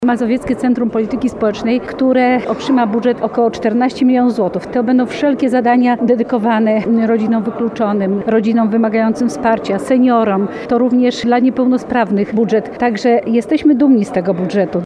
– Udało się zyskać również dodatkowe fundusze na pomoc społeczną – dodaje Lanc.